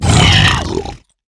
assets / minecraft / sounds / mob / hoglin / death1.ogg
death1.ogg